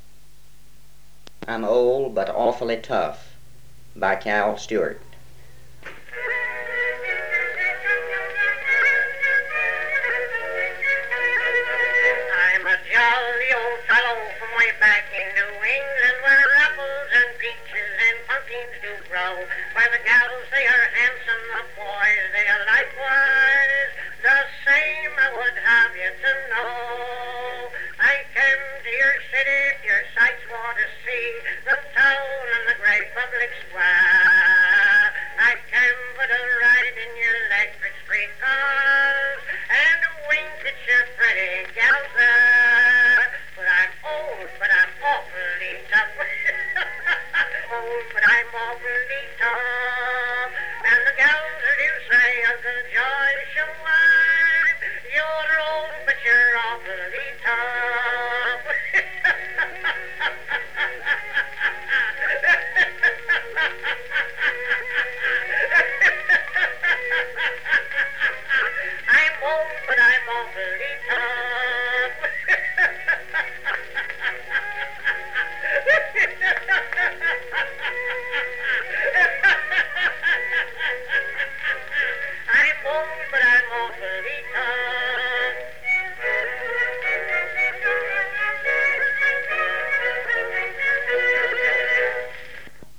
comedy song